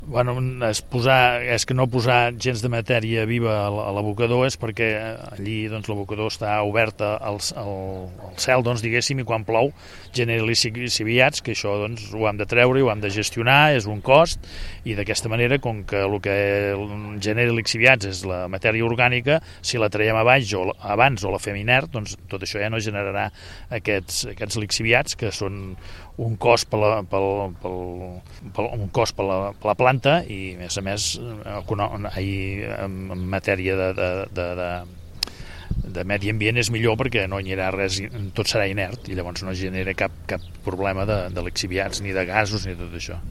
ÀUDIO: Josep Amill explica la millora en el tractament de la fracció orgànica
Josep-Amill-tractament-de-residus-organics.mp3